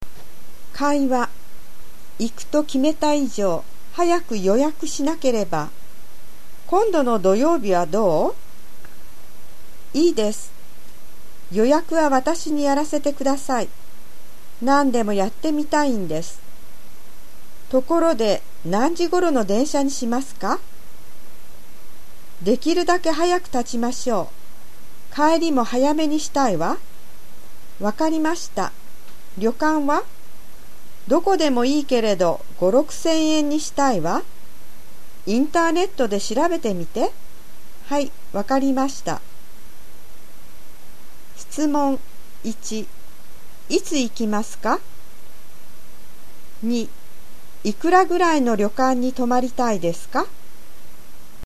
【会話】(conversation)